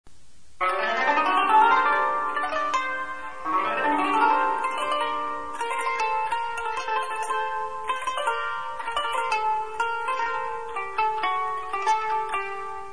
Audio clip of a Kanoon
Kanoon(Kanoun) is a lap-harp with approximately 72 strings, tuned in sets of three. It is plucked with finger-picks extended from each index finger held by a metal circular band.
kanoon.mp3